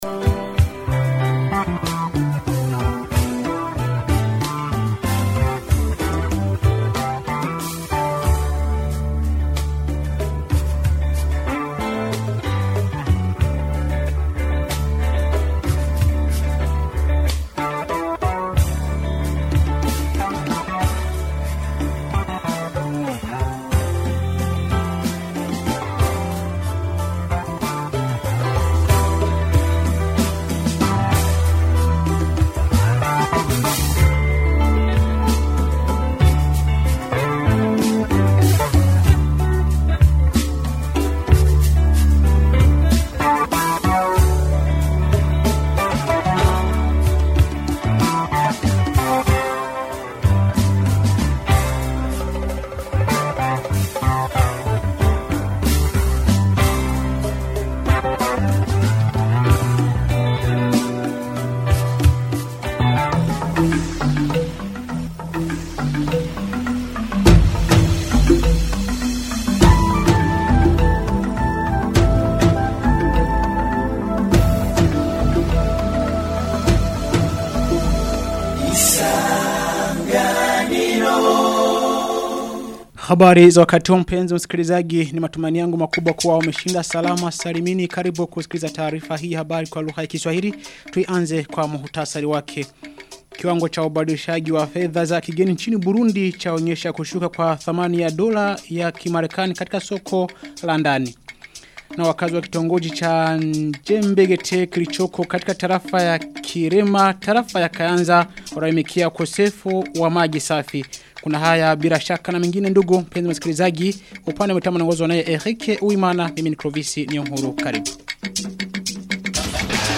Taarifa ya habari ya tarehe 17 Oktoba 2025